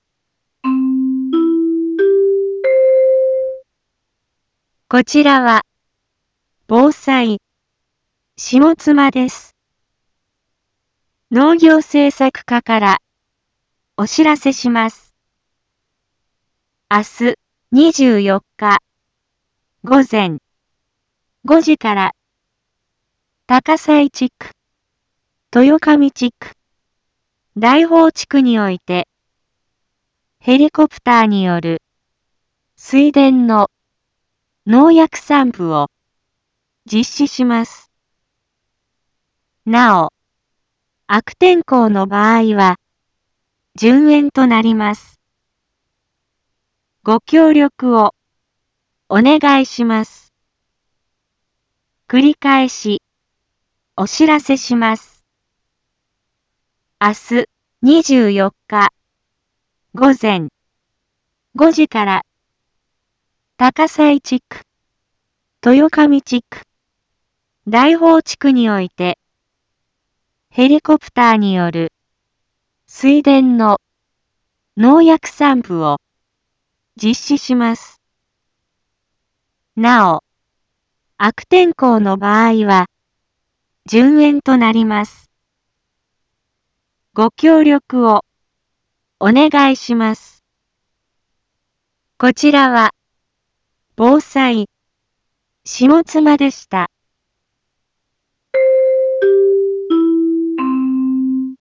一般放送情報
Back Home 一般放送情報 音声放送 再生 一般放送情報 登録日時：2025-07-23 12:36:59 タイトル：農林航空防除について インフォメーション：こちらは、ぼうさいしもつまです。